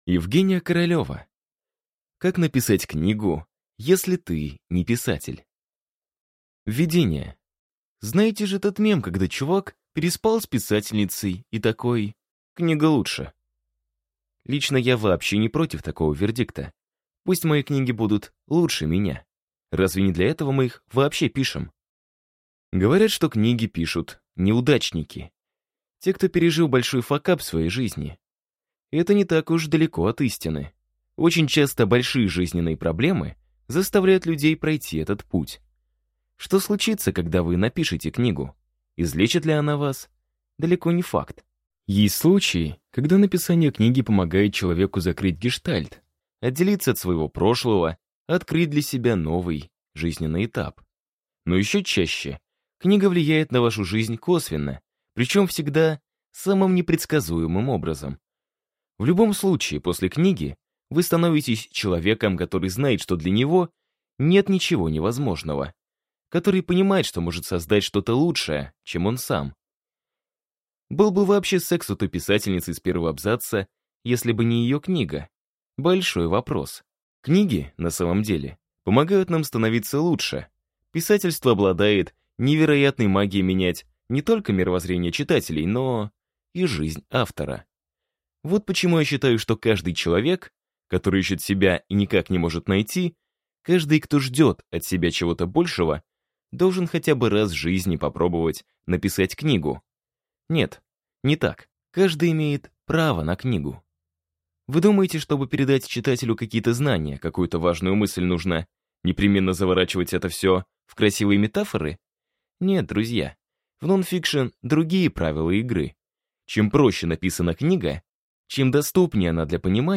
Аудиокнига Как написать книгу, если ты не писатель | Библиотека аудиокниг